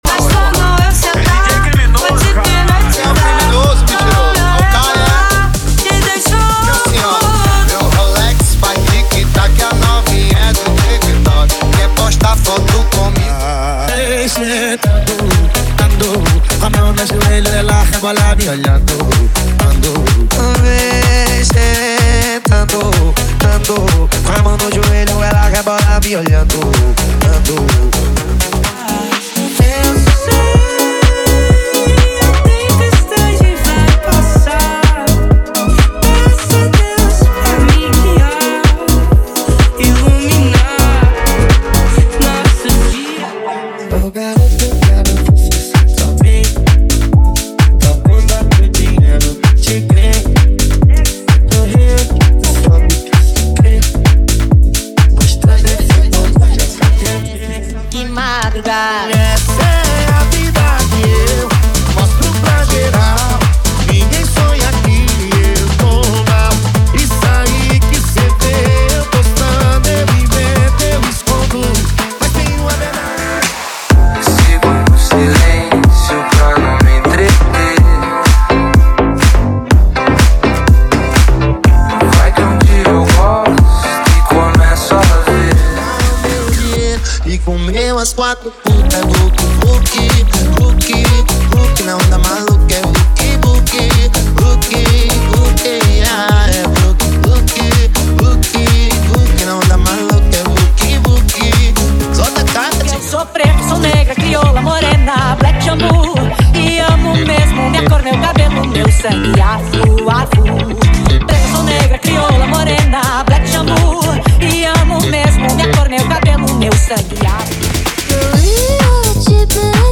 Remix Nacinais: Prévias
– Sem Vinhetas